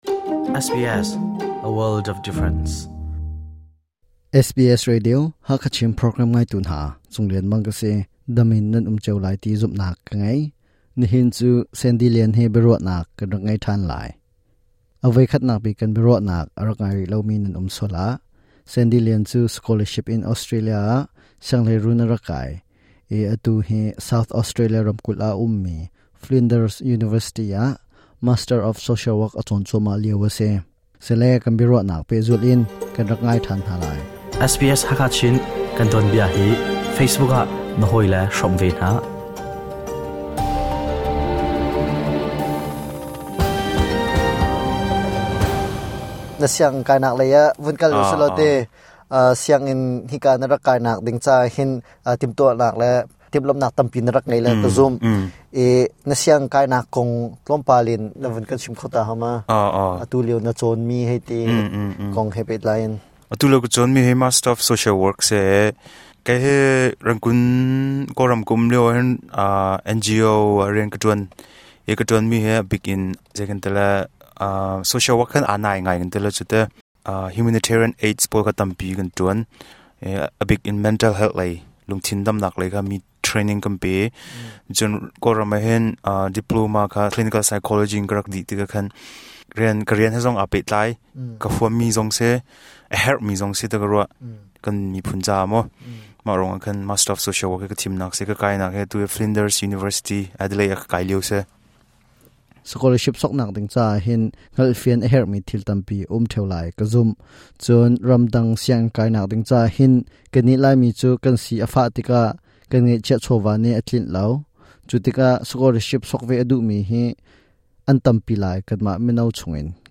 SBS Hakha Chin Radio biaruahnak kan ngei kho.